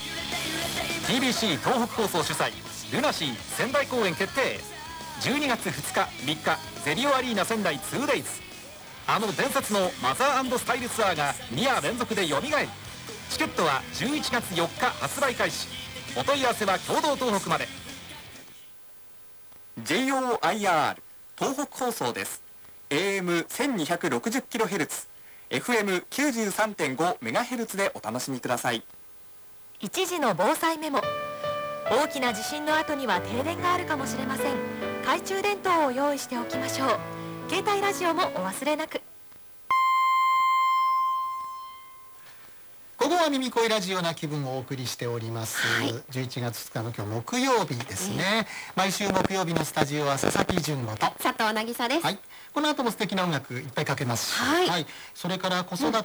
イヤホン端子に ＩＣ録音機を繋ぎ録音